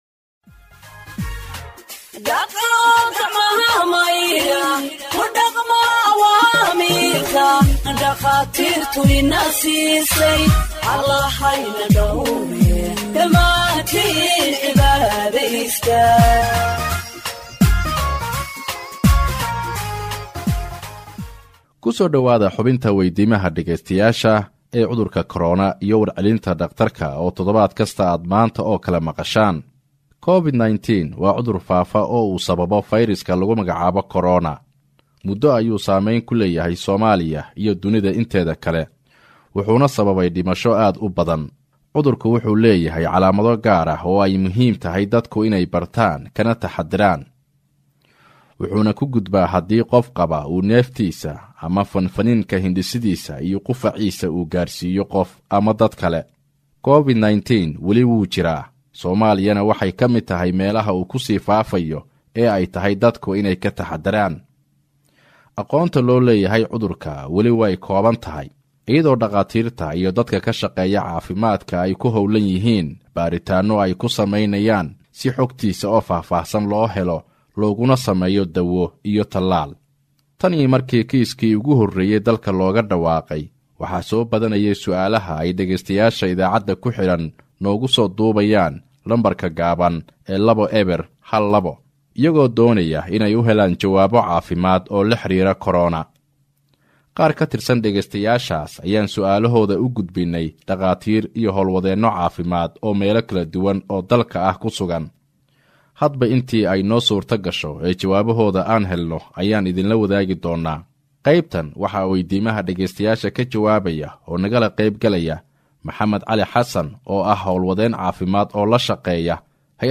HEALTH EXPERT ANSWERS LISTENERS’ QUESTIONS ON COVID 19 (57)